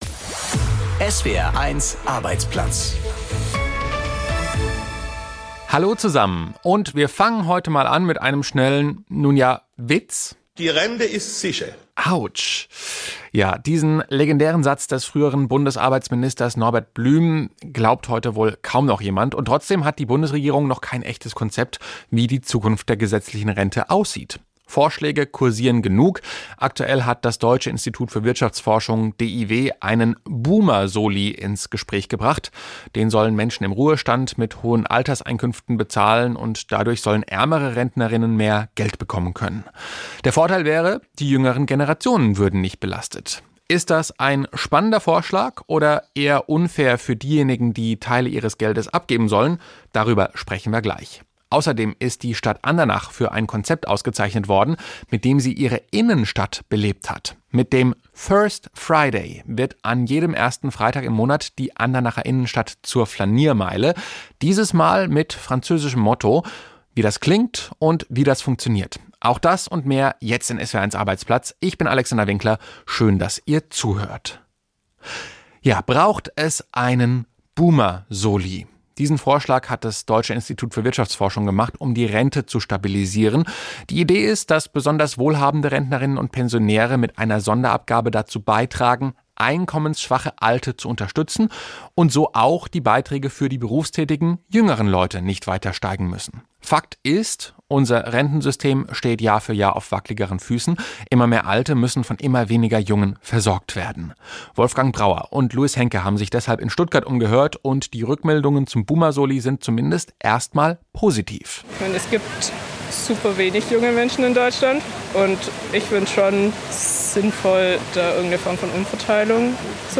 Sollen die Älteren das Rentensystem allein stabilisieren? ++ Interview mit Prof. Bernd Raffelhüschen, Rentenexperte der Uni Freiburg ++ Wie der "First Friday" die Innenstadt von Andernach belebt ++ Arbeitsrecht: Nestlé-Chef wegen Liebschaft gekündigt: Denkbar in Deutschland?